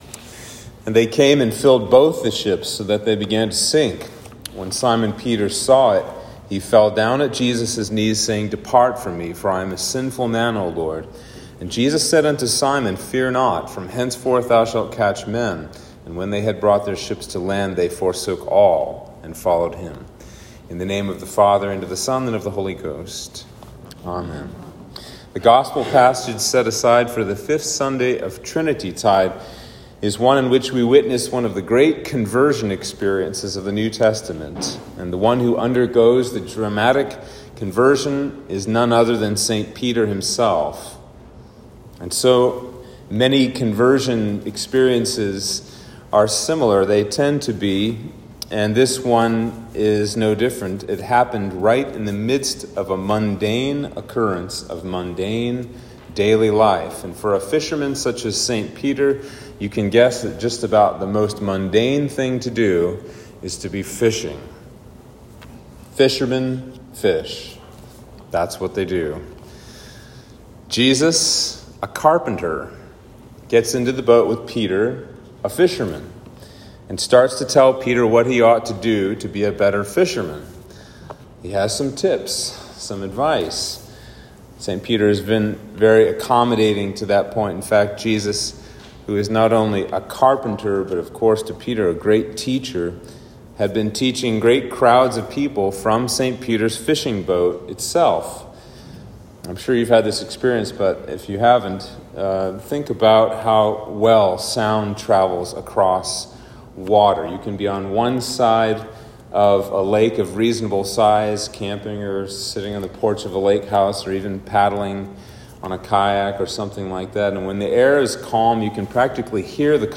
Sermon for Trinity 5